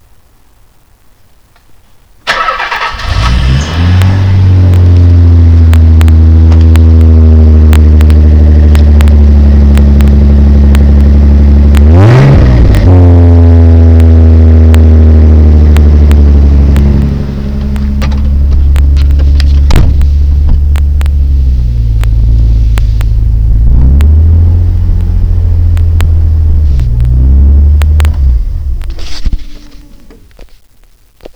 The car is now wonderfully quiet - not quite OEM quiet but much better than basically the same exhaust system on the NA engine.
red-w-exhaust.wav